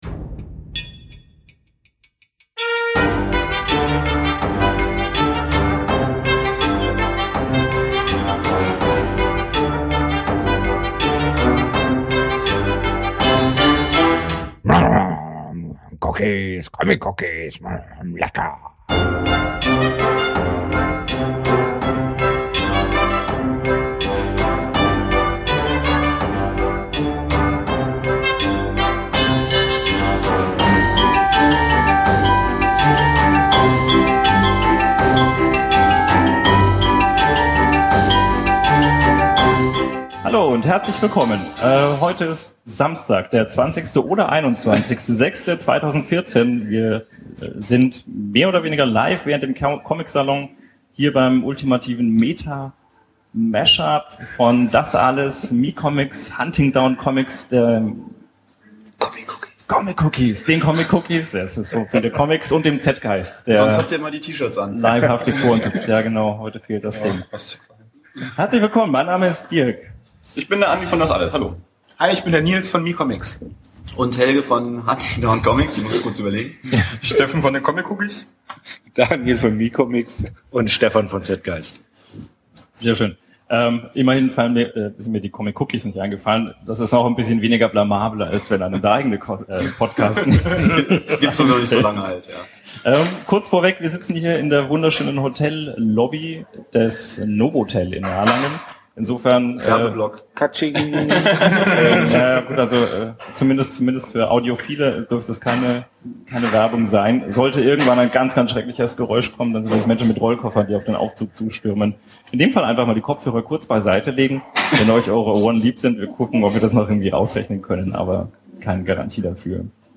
Wie in Episode 25 bereits angekündigt und angespielt, fand am Samstag, den 21. Juni 2014 im Rahmen des Comic-Salons im Erlanger Novotel ein Treffen von Comic-Podcasts statt.
In dieser Spezial-Ausgabe der Comic-Cookies könnt ihr die Aufzeichnung dieses Treffen nun hören.